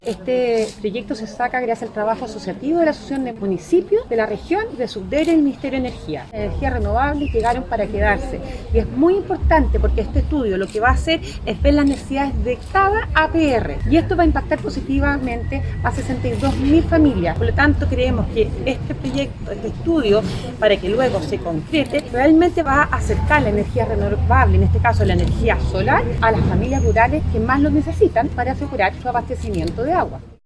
La presentación de la iniciativa se efectuó en la sede del Comité de Agua Potable Rural de La Palma, en Quillota y fue encabezada por el presidente de la Asociación Regional de Municipalidades y alcalde de Limache, Daniel Morales Espíndola.